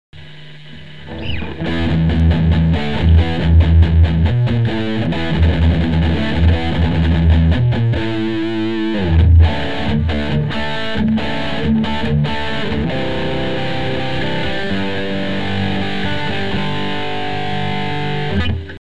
Gitara UPG, snimac KA PAF. V kazdom preste som vypol reverb, nech je menej pukancov, mam slaby pocitac, nie?
Sumu je tam neurekom, aj preto hravam cca na 1/3 gaine ako si mal nastaveny ty.
Mp3 su stereo, pre tvoje pohodlie.
okrem toho ti to este pulzuje hlasitostne - tvrdo to limutuje, co znamena ze mas prevaleny este aj output